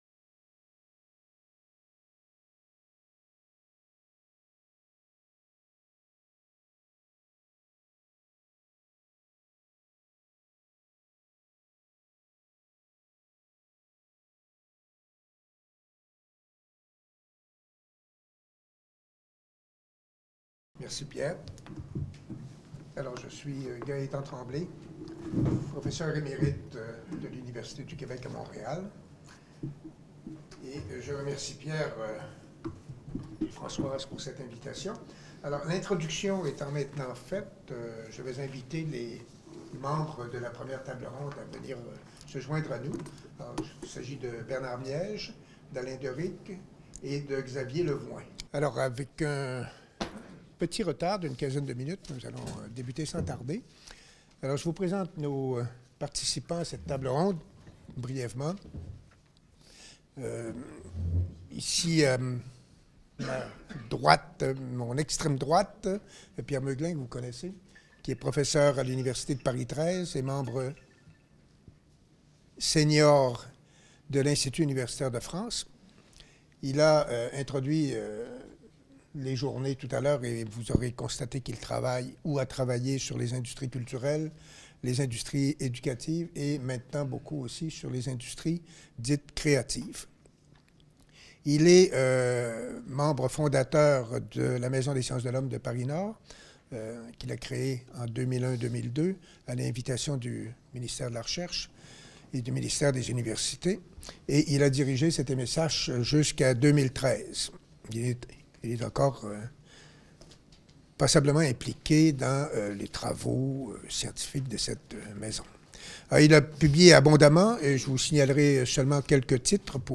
L’industrialisation de l’éducation 1ère table ronde : Un cadre théorique pour appréhender les phénomènes d’industrialisation de la formation ?